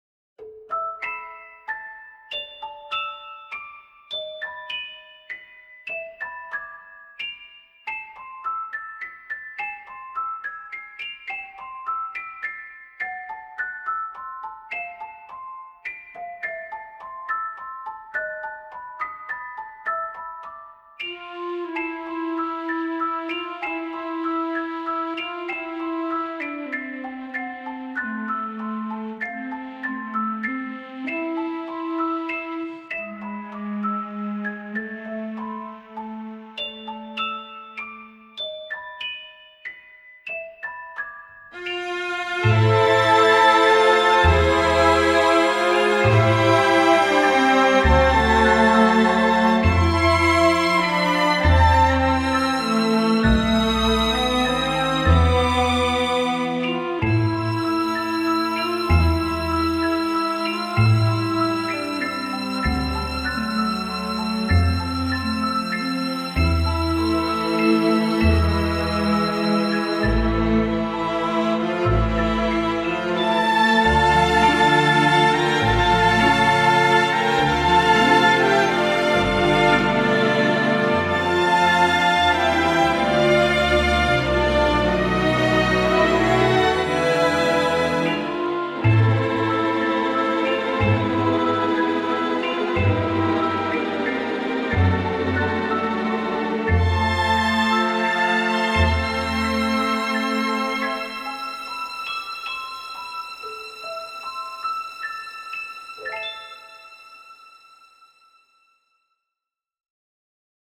INSTRUMENTAL AUDITION TRACKS - USE FOR RECORDING